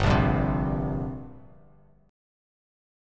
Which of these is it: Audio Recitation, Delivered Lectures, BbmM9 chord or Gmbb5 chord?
Gmbb5 chord